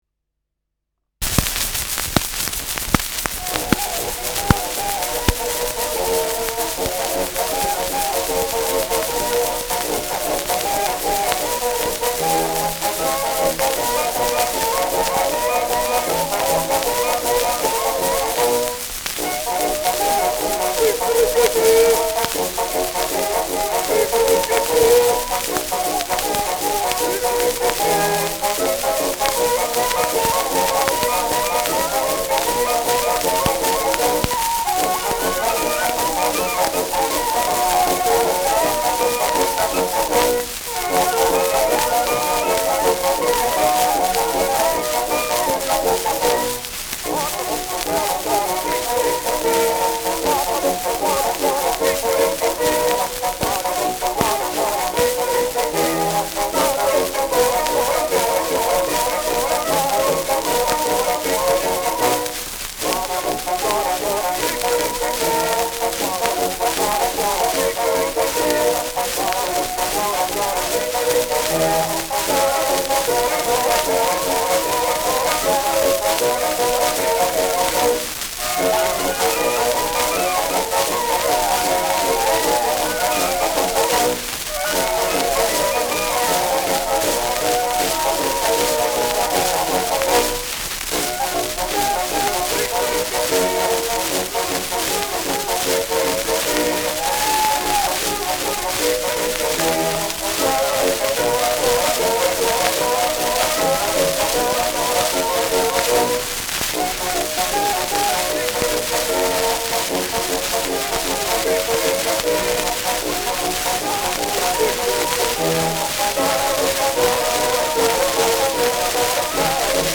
Schellackplatte
ausgeprägtes Rauschen : Knacken zu Beginn